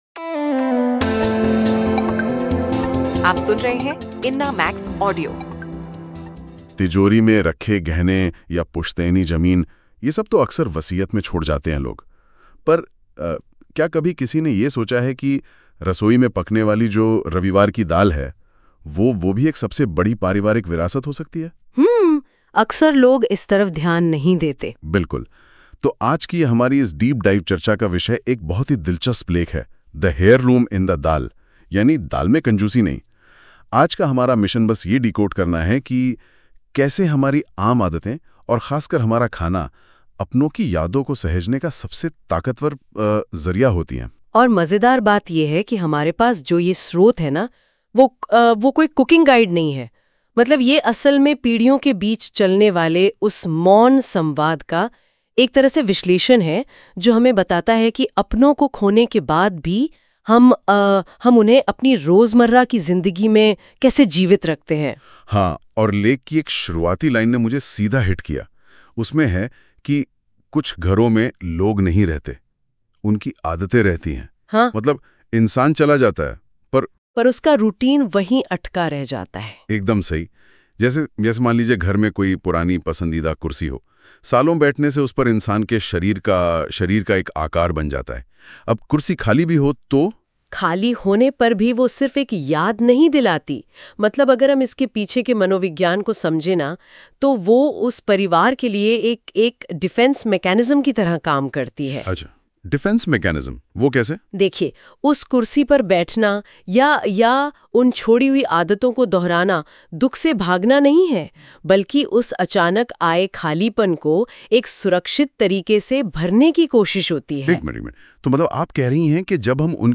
A short Hindi family story about grief, memory, and love.